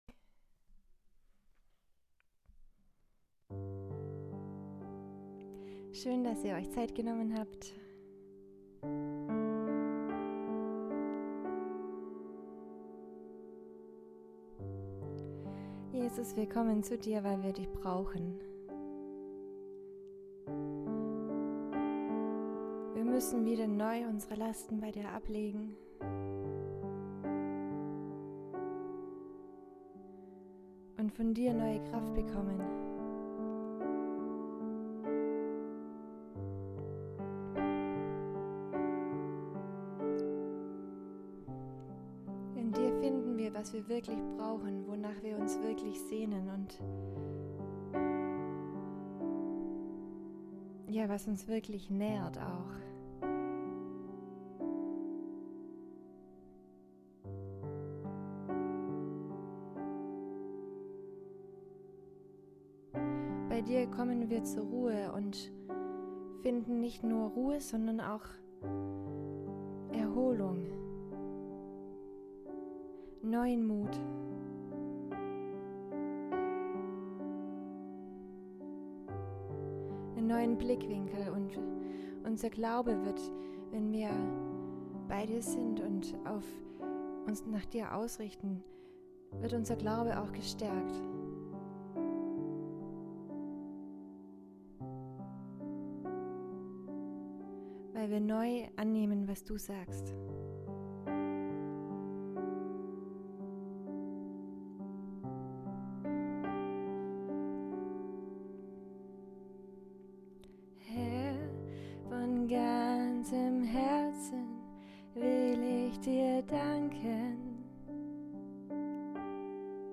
Soaking